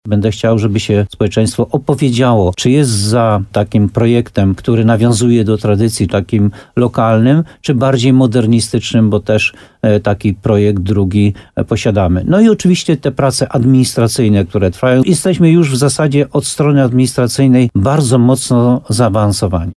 Burmistrz Muszyny Jan Golba, który był gościem programu Słowo za słowo w RDN Nowy Sącz udostępnił już wizualizacje na swoim profilu facebookowym.